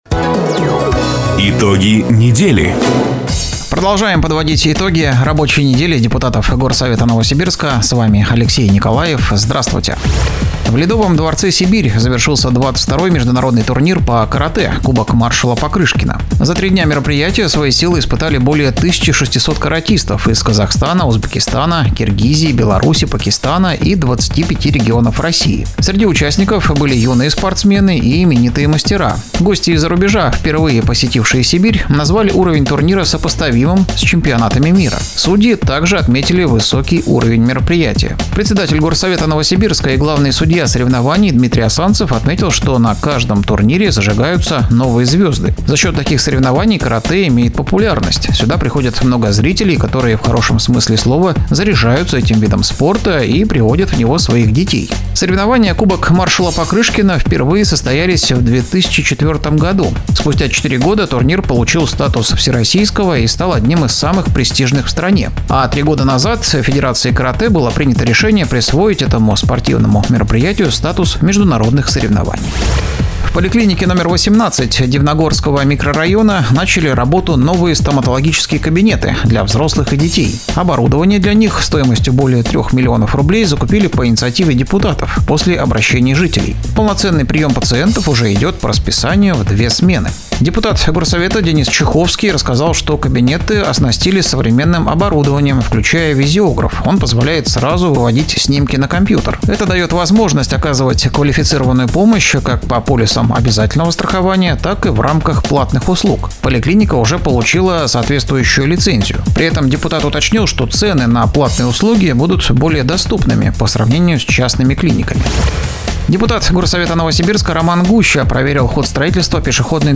Запись программы "Итоги недели", транслированной радио "Дача" 12 октября 2025 года